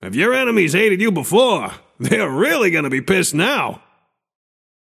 Shopkeeper voice line - If your enemies hated you before, they’re really gonna be pissed now!